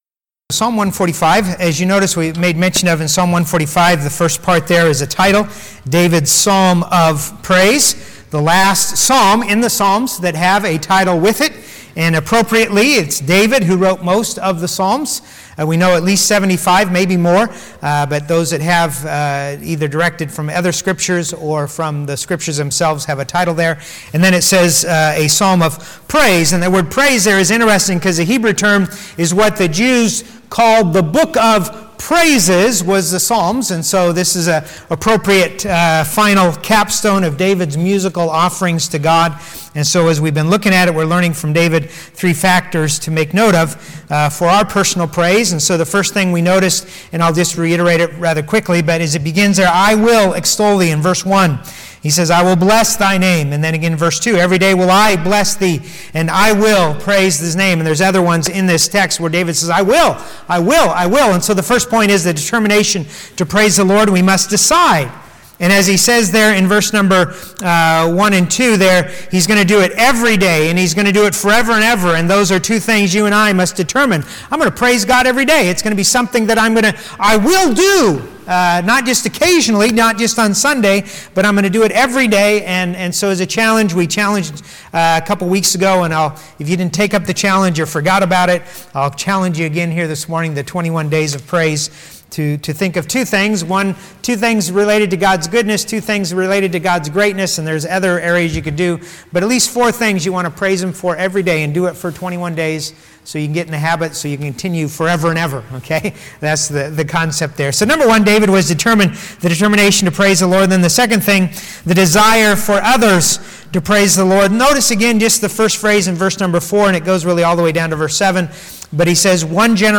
Sunday AM | Psalm 145:11-21